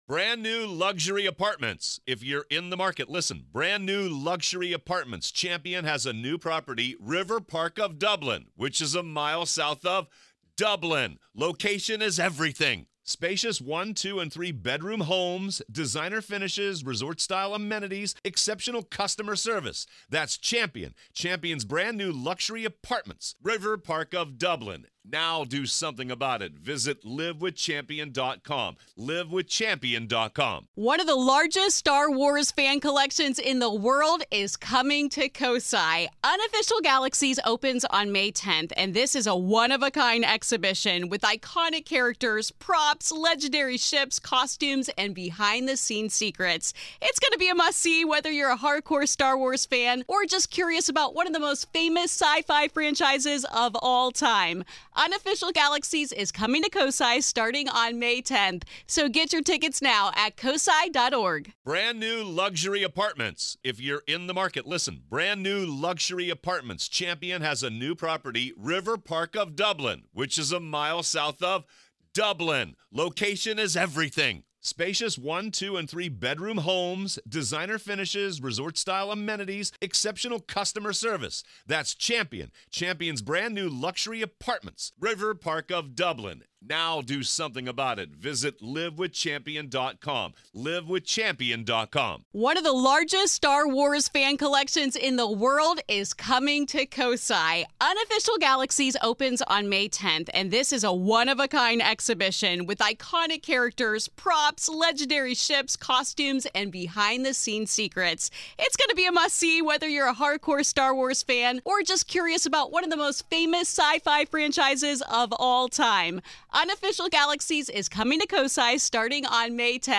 Today, we hear the story of her experiences at the Myrtles Plantation. This is Part Two of our conversation.